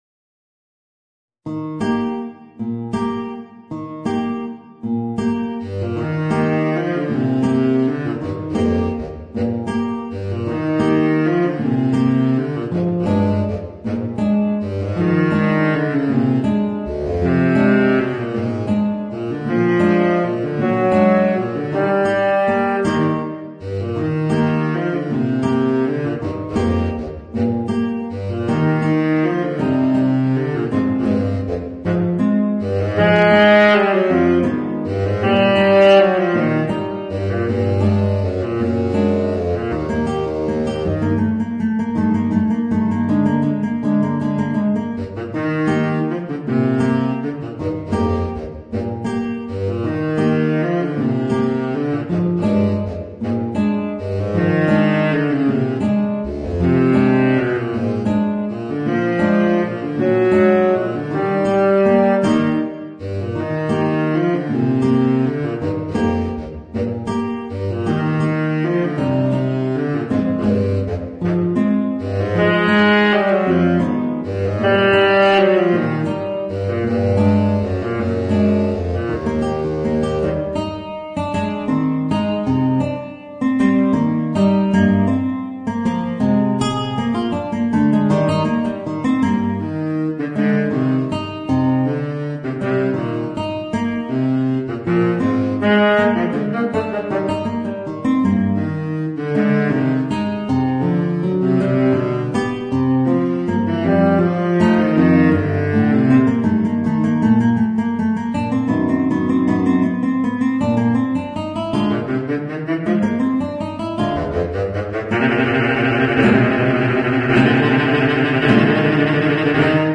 Voicing: Baritone Saxophone and Guitar